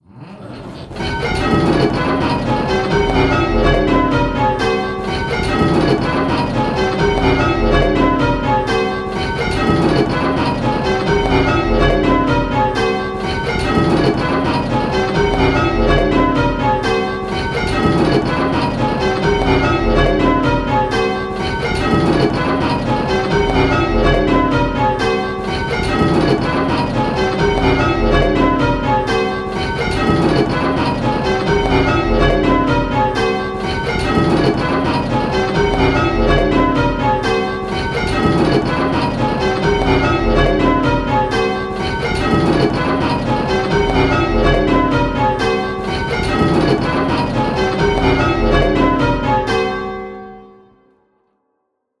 Hearing 8 bells...
We know that hearing specific bells can be difficult, so in these clips we have kept it very simple… in each clip 1 bell is ringing early… and consistently at both hand and backstroke.
Click the play button and listen to the rhythm of the bells…. the rhythm will sound a bit lumpy!
Rounds-8-3E.m4a